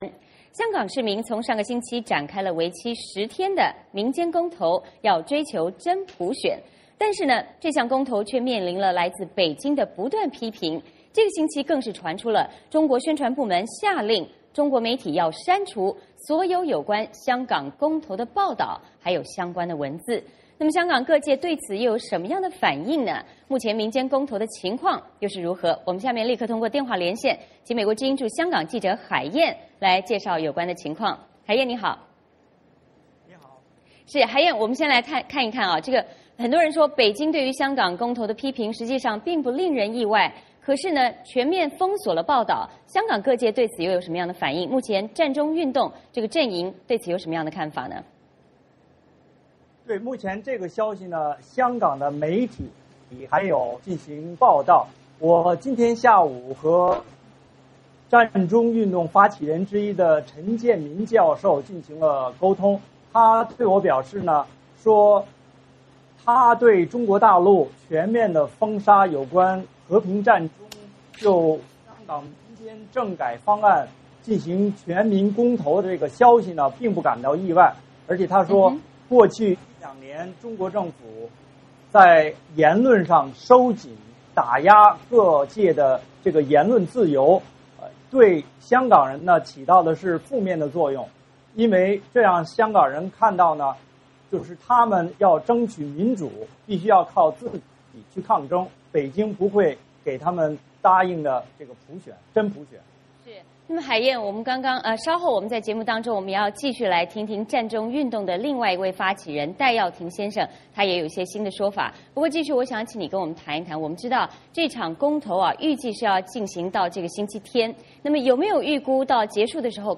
VOA连线：北京封杀香港公投报道